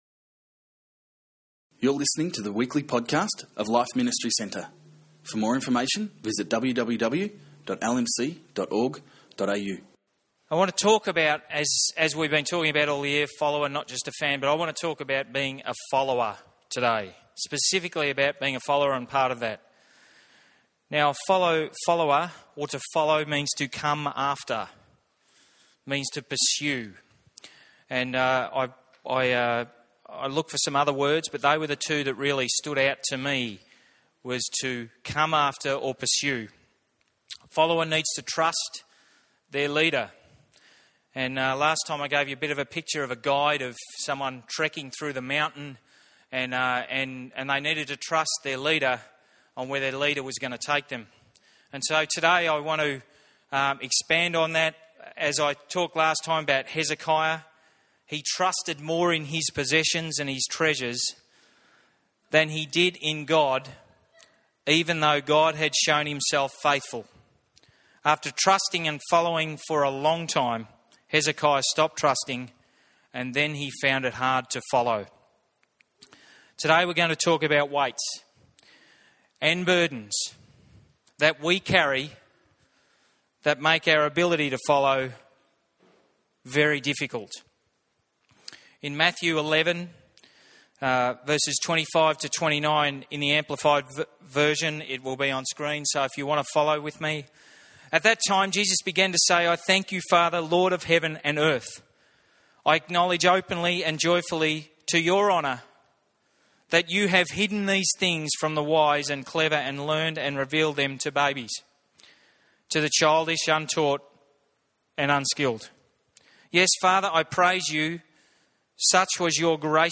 with his message on "The Follower". In this message, he spoke about the different burdens that we sometimes carry, and how we can let God take the weight from us.